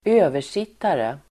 Ladda ner uttalet
Uttal: [²'ö:ver_sit:are]